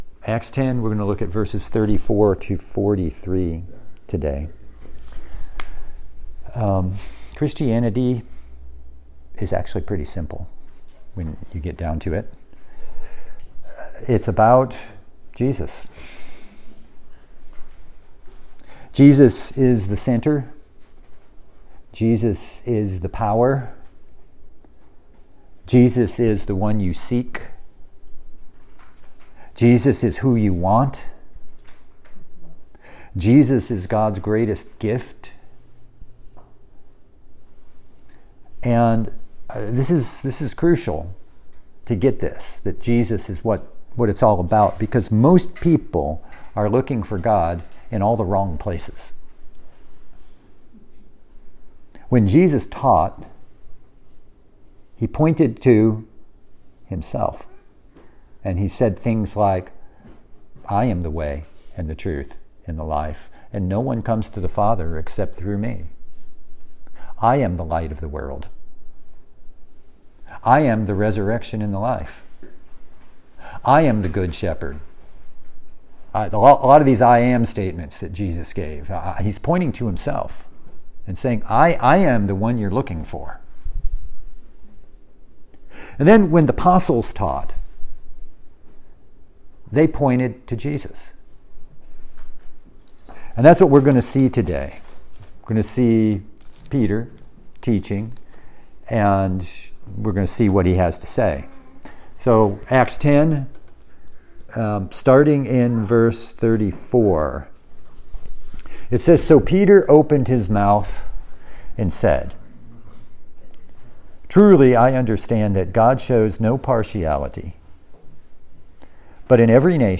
Passage: Acts 10:34-43 Service Type: Sunday